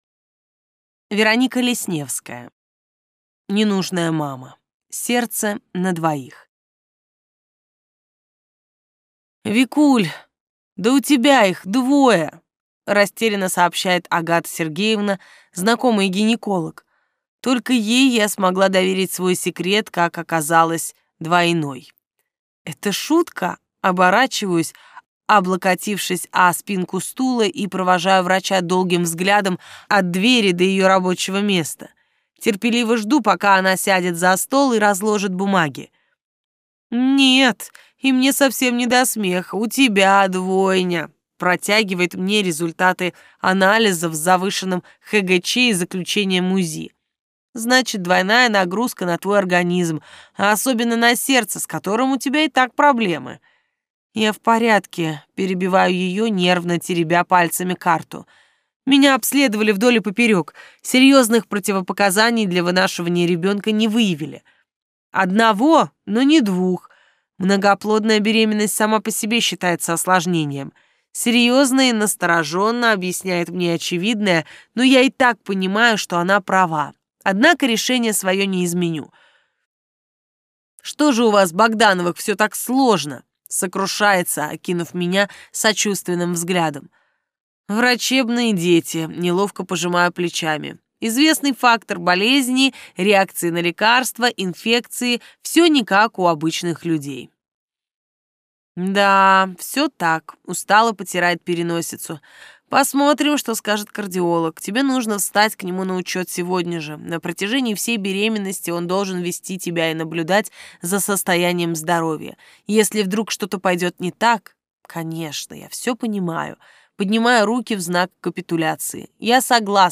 Сокровища чистого разума (слушать аудиокнигу бесплатно) - автор Вадим Панов